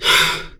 Heavy Breaths
BREATH1M  .1.wav